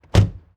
关车门.mp3